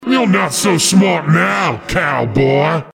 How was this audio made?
This is an audio clip from the game Team Fortress 2 .